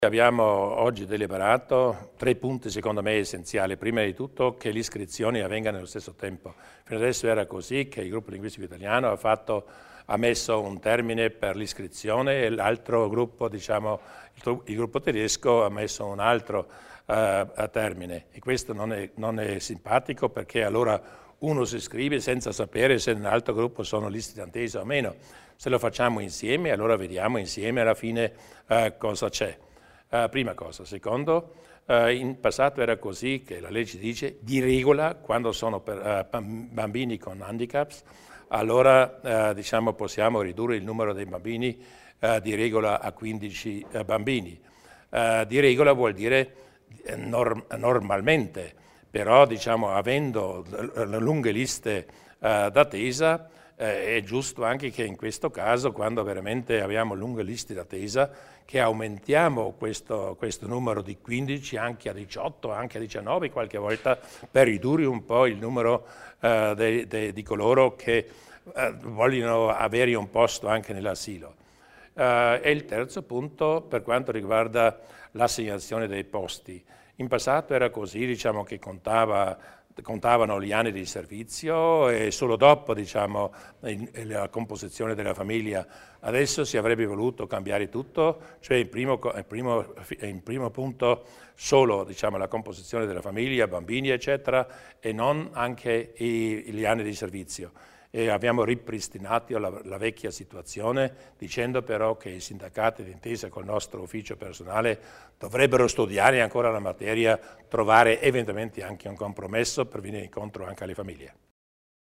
Il Presidente Durnwalder spiega le novità nel settore degli asili altoatesini